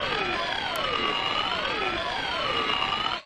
Sci-Fi Ambiences
AFX_BATTLESTATIONS_2_DFMG.WAV